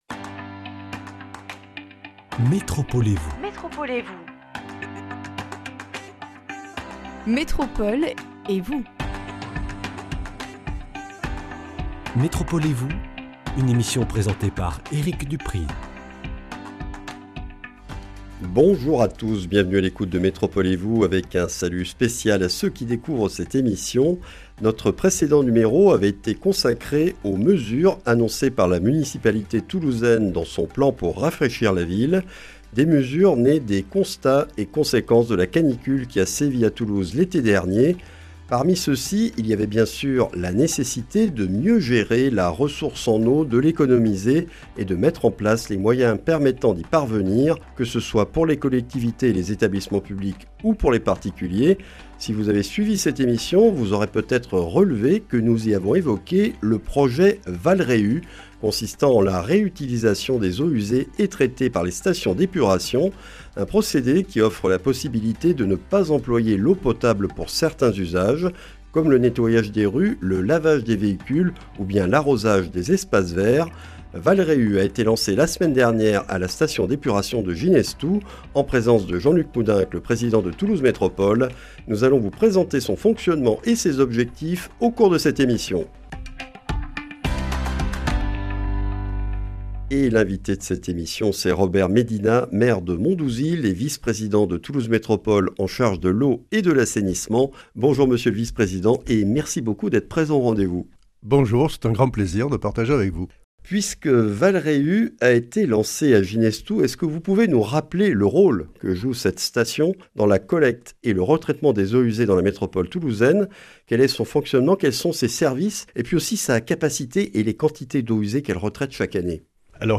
Lancé récemment à Ginestous, Val’Réu est un projet de réutilisation des eaux usées traitées par les stations d’épuration pour remplacer l’eau potable consommée dans le nettoyage des réseaux de canalisations et des rues, le lavage de véhicules ou l’arrosage d’espaces verts. Présentation avec Robert Médina, maire de Mondouzil et vice-président de Toulouse Métropole (Eau et Assainissement).